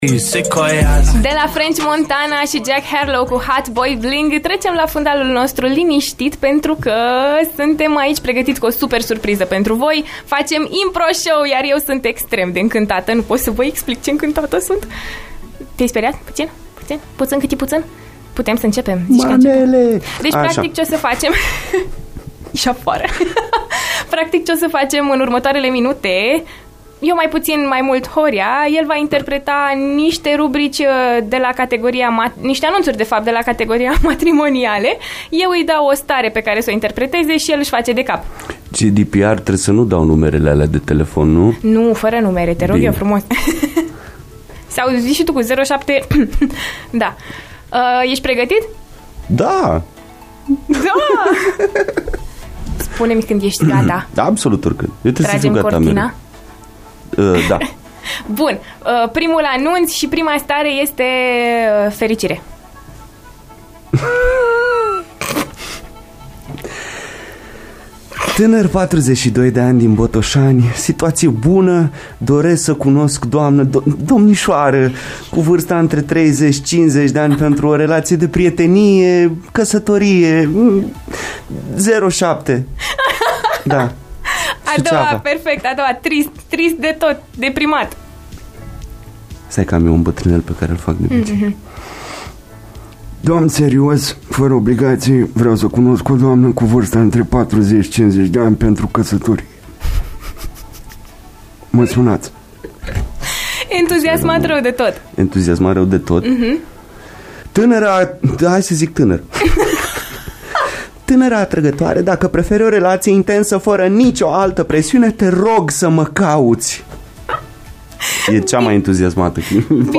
IMPROSHOW.mp3